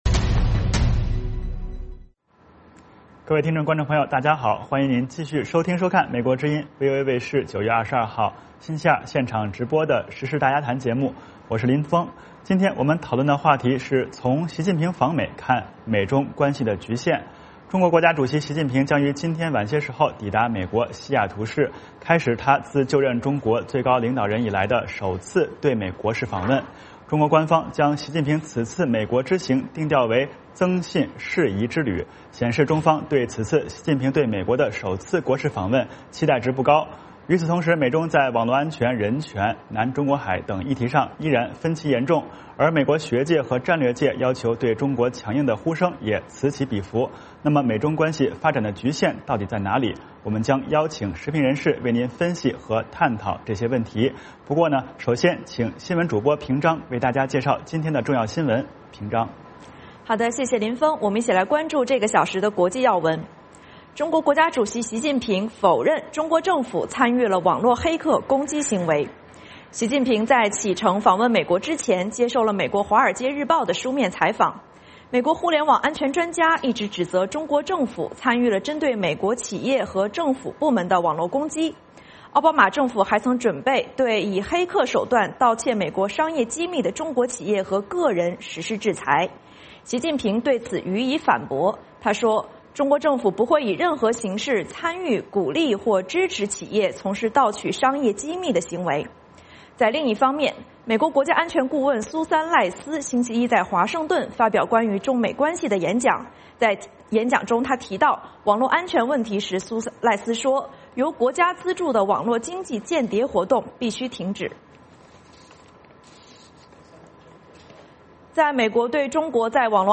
VOA卫视第二小时内容包括重要国际新闻以及时事大家谈。今天我们讨论的话题是从习近平访美看美中关系的局限。中国国家主席习近平将于今天晚些时候抵达美国西雅图市，开始他自就任中国最高领导人以来的首次对美国事访问。